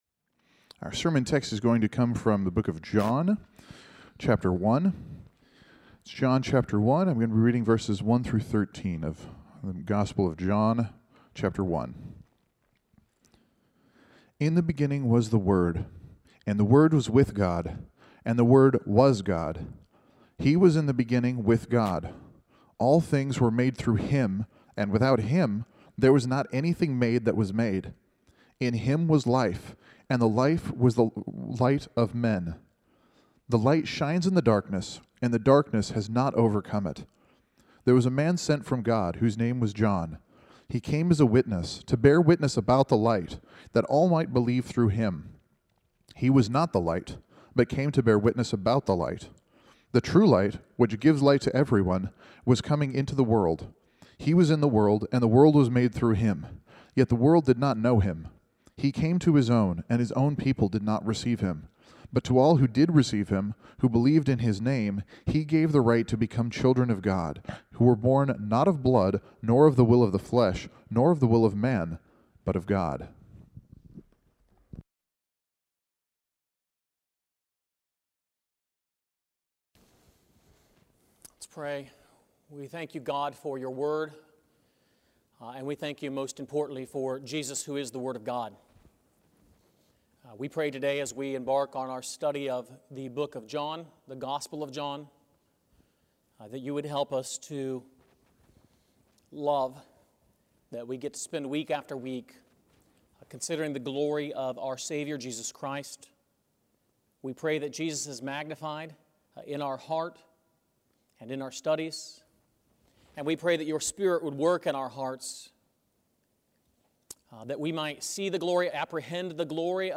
Sermons on the Gospel of John